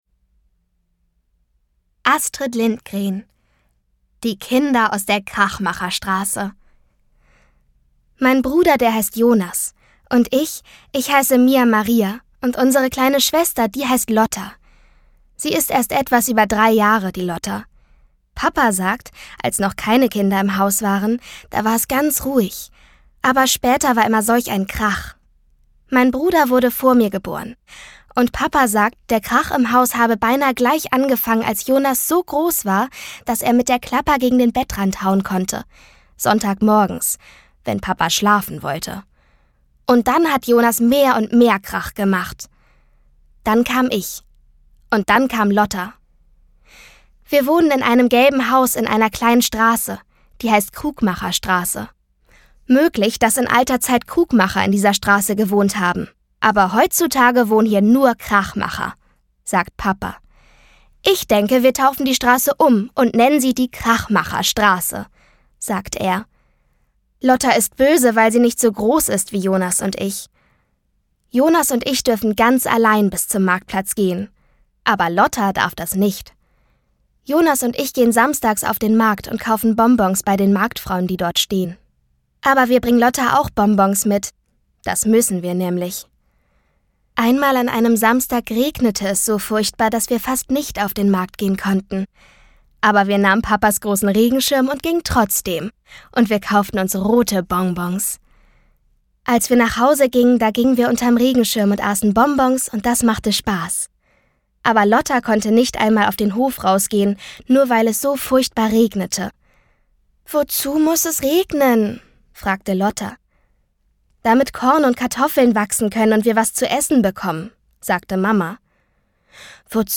Schlagworte Abenteuer • Abenteuer; Kinder-/Jugendliteratur • Alltag • Bullerbü • draußen spielen • Familie • Freunde • Freundschaft • Geschwister • Groß werden • Hörbuch; Lesung für Kinder/Jugendliche • Kinder • Kinderalltag • Kinderhörbuch • Klassiker • LOTTA • lustig • Multibox • Nachbarschaft • Schweden • Skandinavien